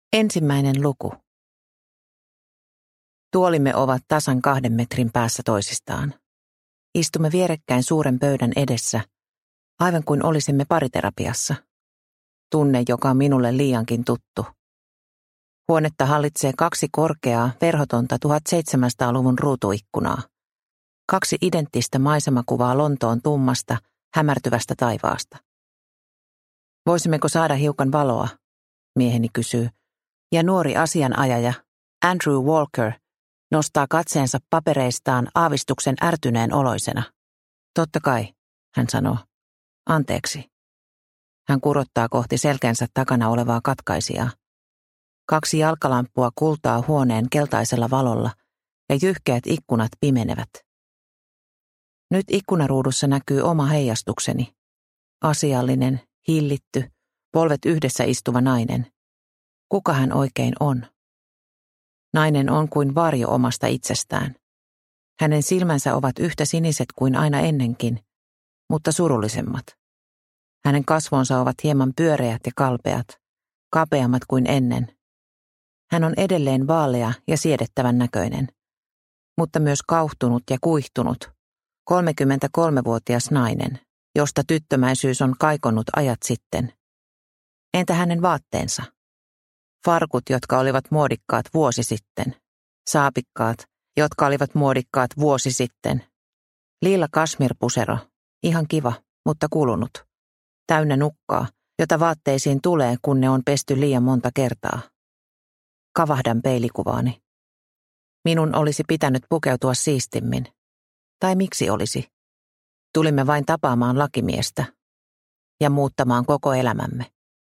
Jääkaksoset – Ljudbok – Laddas ner